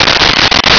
Sfx Holo On
sfx_holo_on.wav